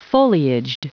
Prononciation du mot foliaged en anglais (fichier audio)
Prononciation du mot : foliaged